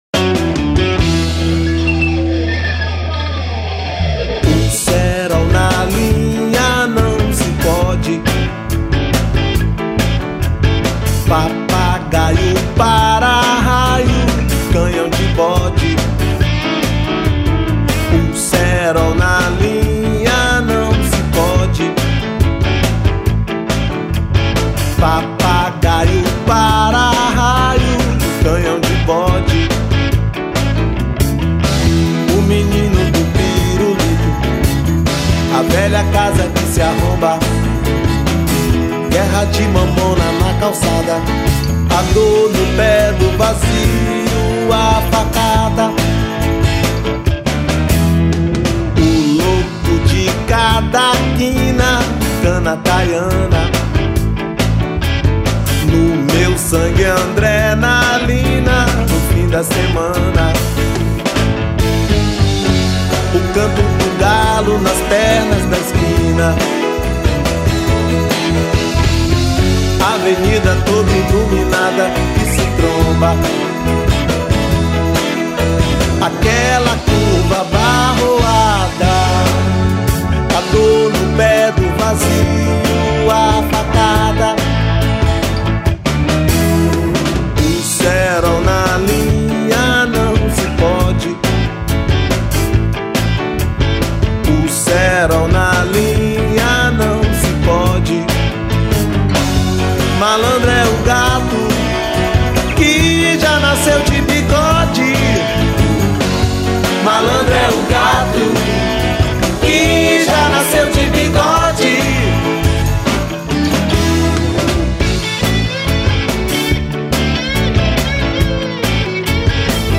1289   03:12:00   Faixa:     Rock Nacional
Guitarra
Baixo Elétrico 6
Percussão
Vocal
Teclados